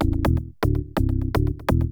SNTHBASS018_PROGR_125_A_SC3(L).wav